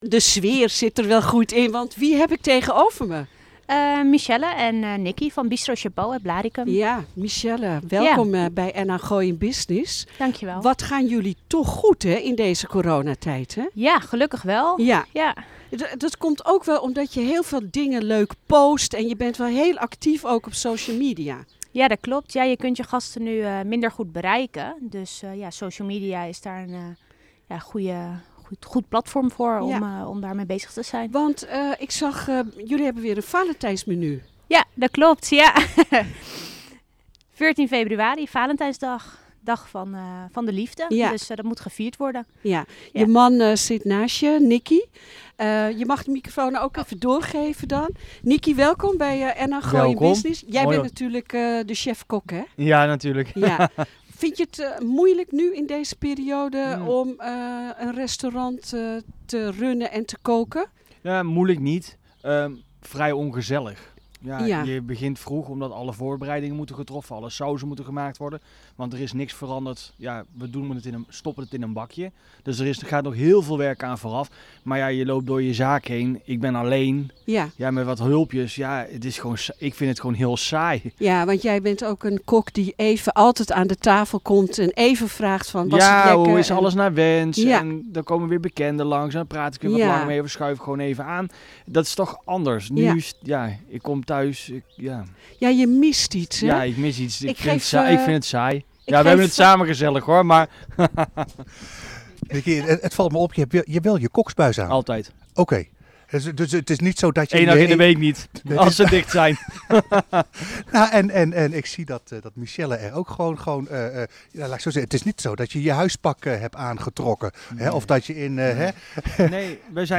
in de achtertuin